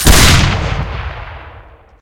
shottyShoot.ogg